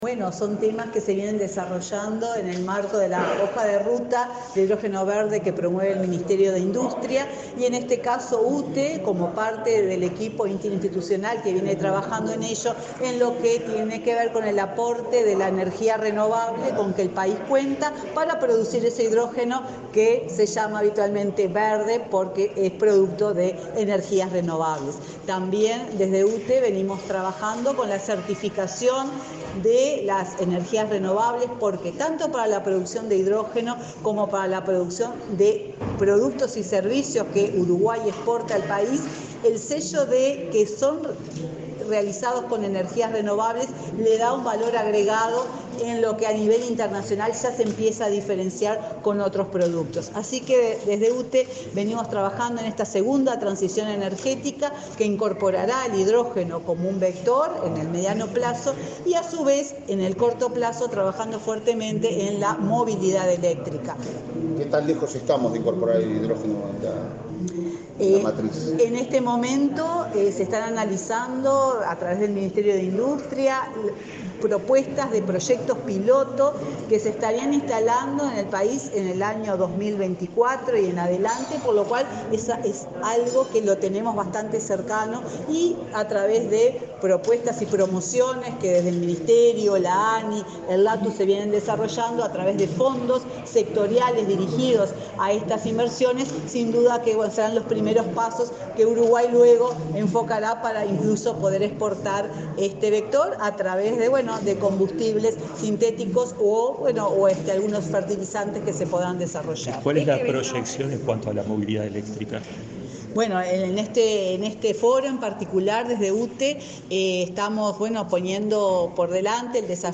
Declaraciones de la presidenta de UTE, Silvia Emaldi
La presidenta de UTE, Silvia Emaldi, participó este viernes 25 en Punta del Este, Maldonado, en el Segundo Foro Internacional de Movilidad Eléctrica.
Luego dialogó con la prensa.